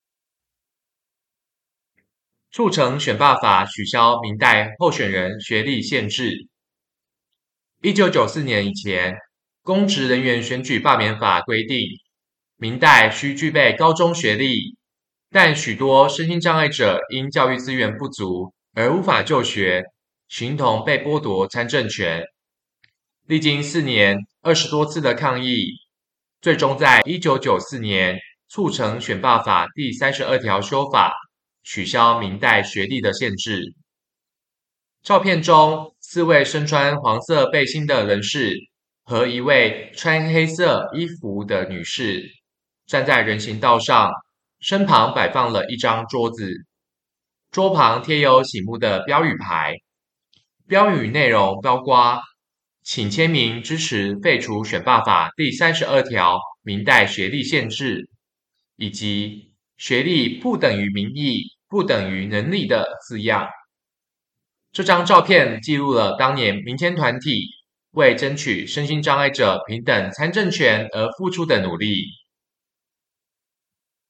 所有文字內容會轉成語音檔，每幅作品旁都附有語音 QR-Code，讓視障朋友或不便閱讀的民眾能掃描聆聽，用「聽」的方式來欣賞作品。